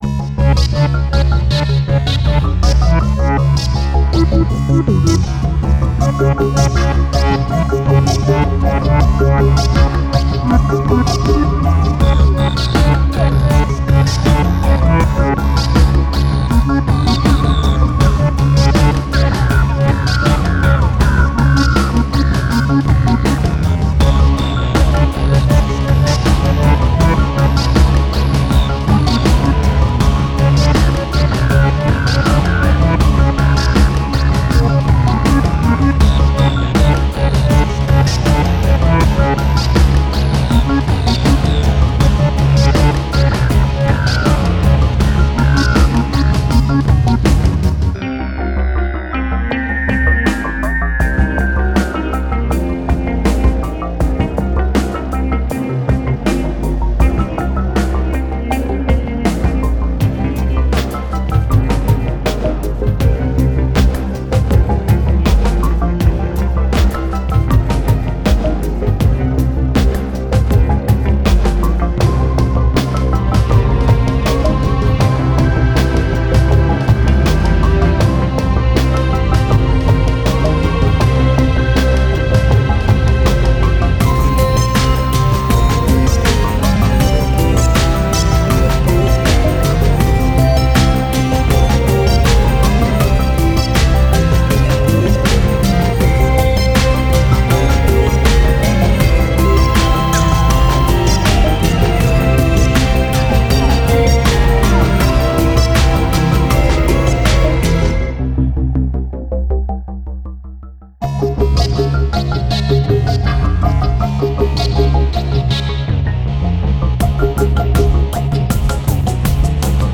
A dark ambient sci-fi theme